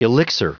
Prononciation du mot elixir en anglais (fichier audio)
Prononciation du mot : elixir